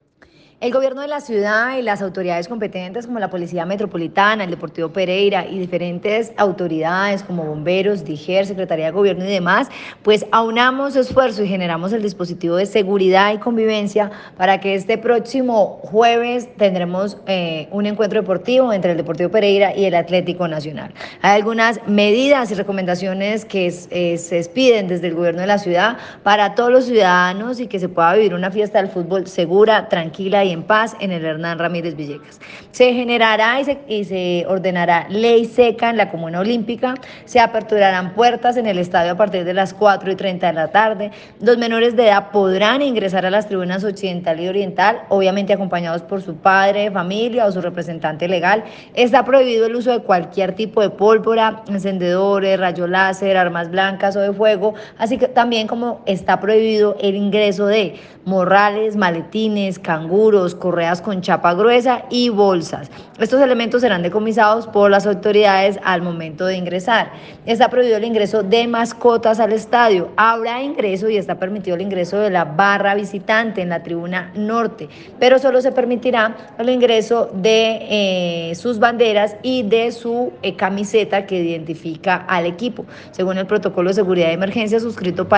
La secretaria de Gobierno, Karen Zape Ayala, informó sobre las medidas de seguridad que se implementarán con la Policía Metropolitana de Pereira para el encuentro deportivo entre Deportivo Pereira y el Atlético Nacional, de acuerdo con los requerimientos de la Comisión Local para la Seguridad, Comodidad y Convivencia del Fútbol Profesional de Pereira.